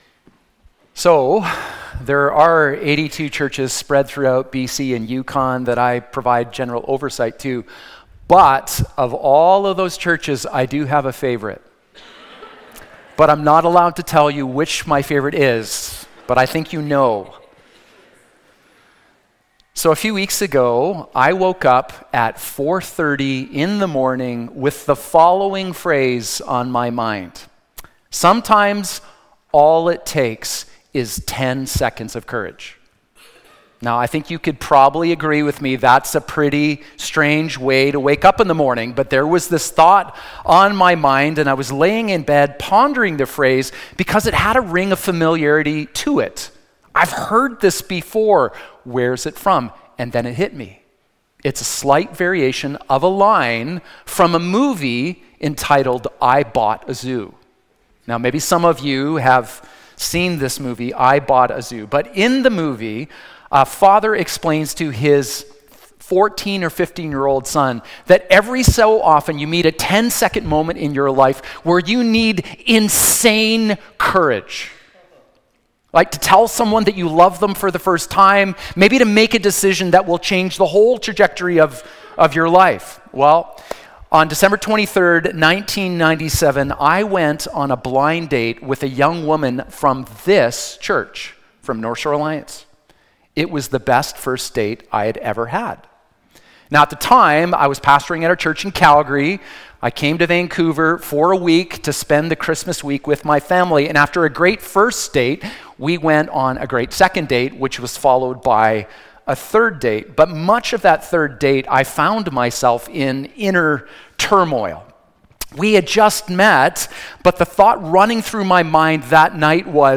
Sermon Podcast Audio Jan 04.mp3